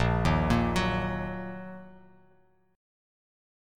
Bbsus4#5 chord